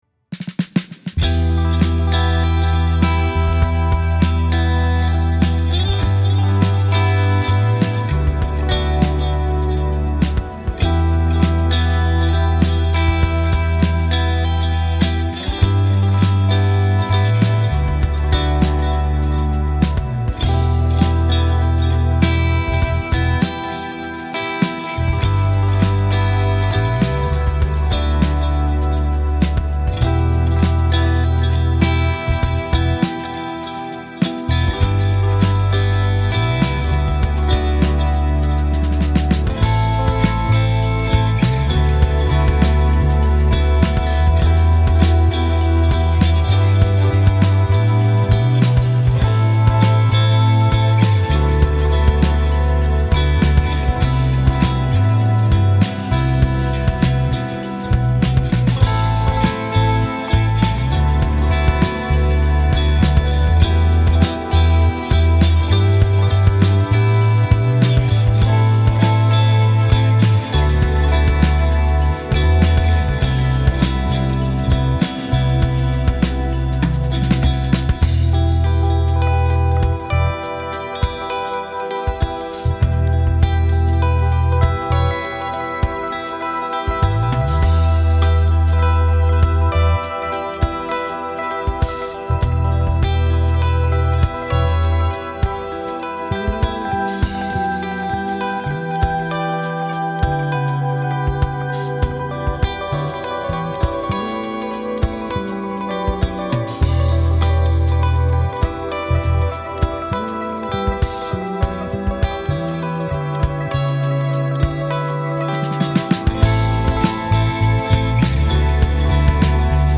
This pack blends ambient depth with fusion-style playability, giving you tones that feel alive under your fingers — from soaring leads to spacious cleans and evolving pads.
• Fusion Lead – Smooth, expressive lead tone with sustain and space
• Fusion Clean – Clear, dynamic clean with subtle ambient depth
• Fusion Pad – Wide, atmospheric layer perfect for textures and intros
• Fusion Bass – Deep, supportive tone for low-end ambient playing
FM4-Ambient-Pack.mp3